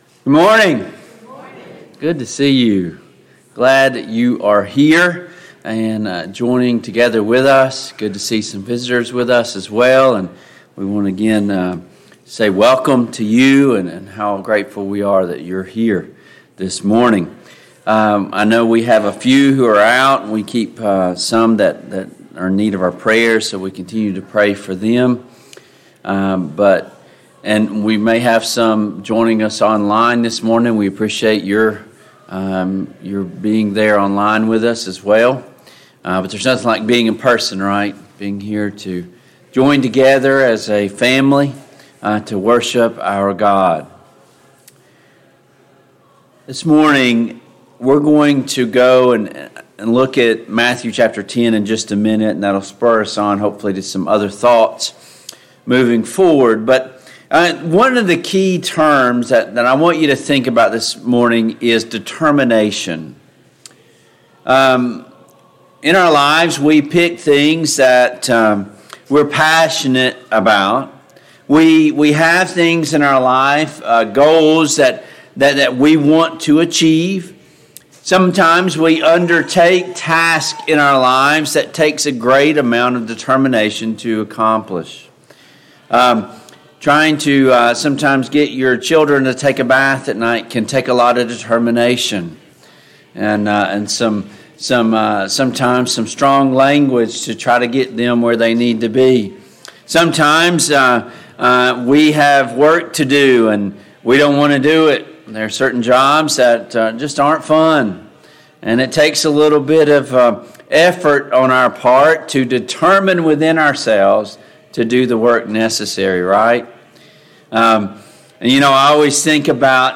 Passage: Matthew 10:27-33, Ephesians 1:4-5, Ephesians 1:11-14, Philippians 2:12-13, 1 Timothy 2:3-4, 2 Peter 3:9, Luke 9:23-24, Service Type: AM Worship Download Files Notes Topics: Self Sacrifice « 3.